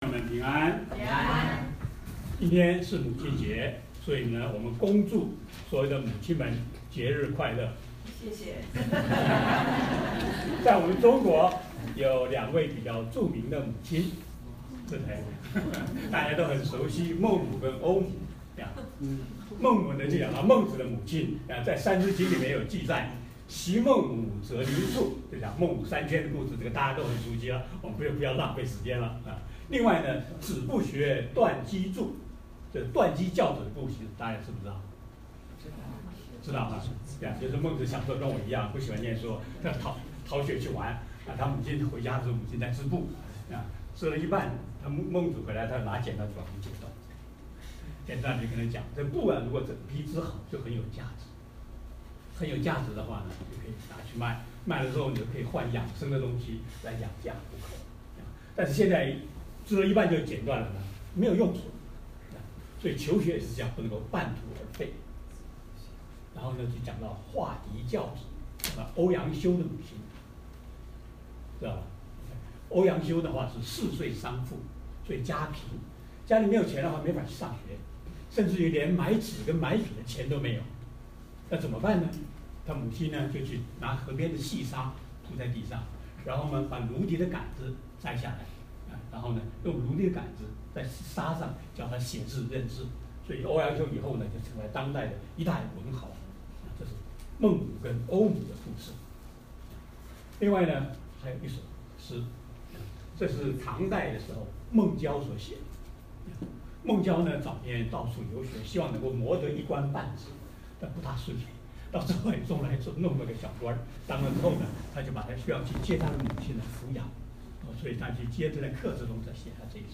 如何播放布道录音